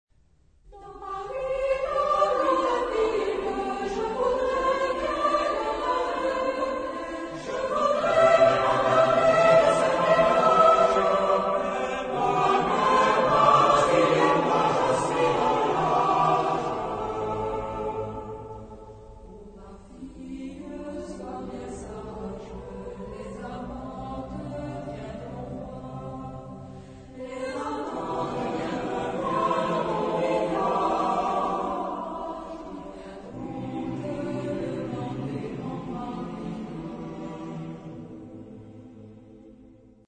Genre-Style-Form: Secular ; Popular
Mood of the piece: moderate
Type of Choir: SMATB  (5 mixed voices )
Tonality: A aeolian
Origin: Lyonnais (F)